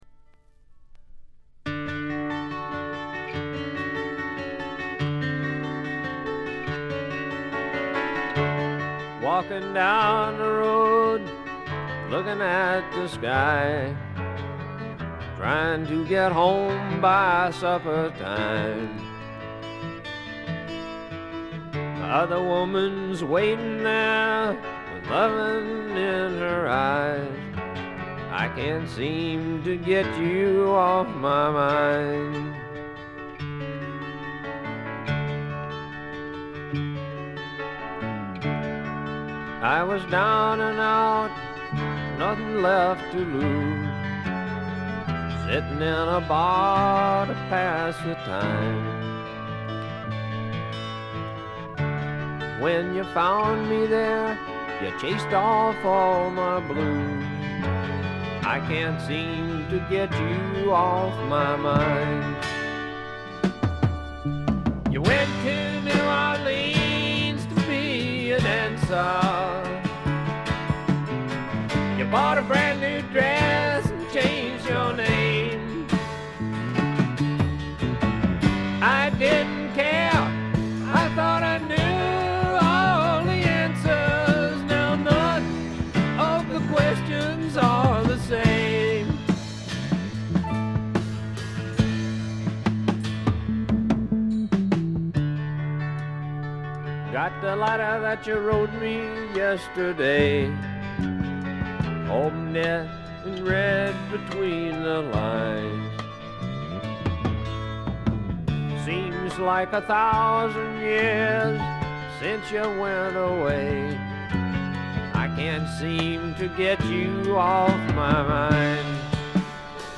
部分試聴ですが、ところどころでチリプチ、散発的なプツ音少し。
いかにもテキサス／ダラス録音らしいカントリー系のシンガー・ソングライター作品快作です。
ヴォーカルはコクがあって味わい深いもので、ハマる人も多いと思いますね。
試聴曲は現品からの取り込み音源です。
Recorded At - January Sound Studio